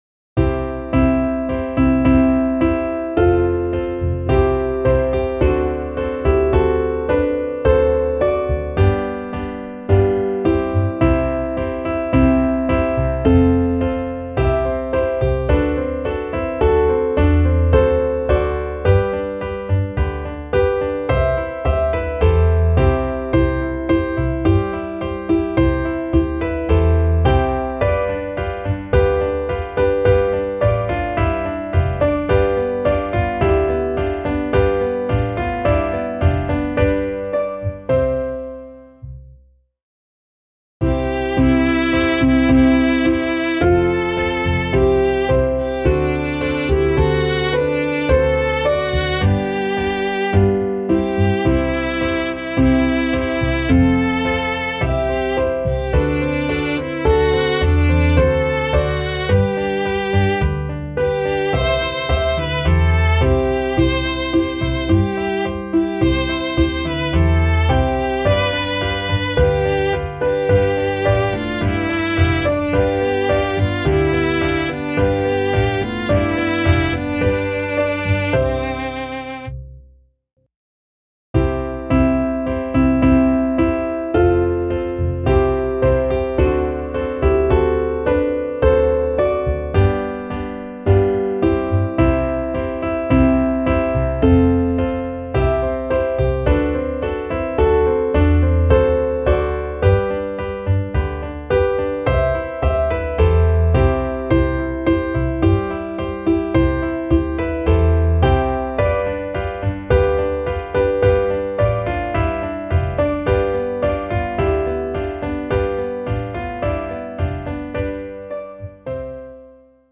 Klaviersatz
midi_singt-dem-leben-euer-lied_klavier_320.mp3